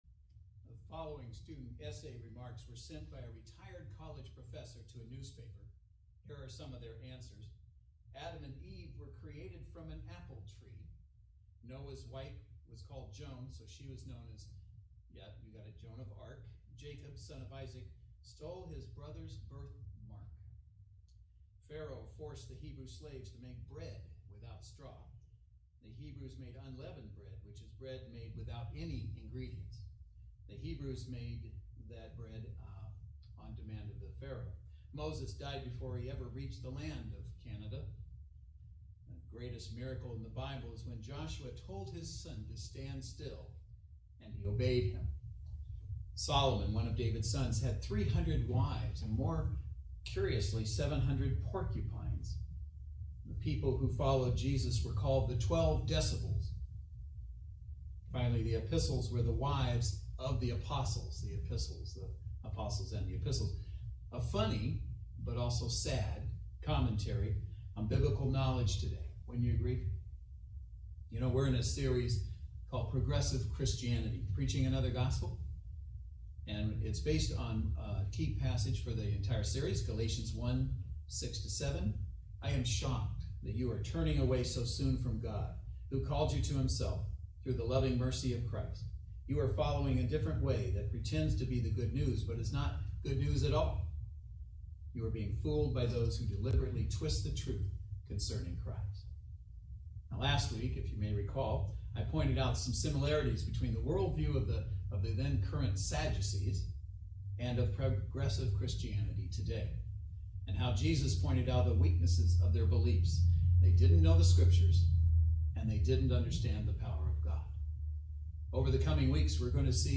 Service Type: Saturday Worship Service The greatest danger to Christians today comes not from outside the Church – but from within.